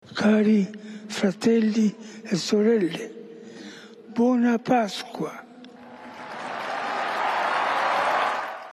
Onlookers cheered and clapped as the Pope today greeted crowds from a balcony overlooking St Peter’s Square.
Speaking in Italian he wished “Dear Brothers and Sisters a Happy Easter” before touring the Vatican City in the ‘Pope-mobile’.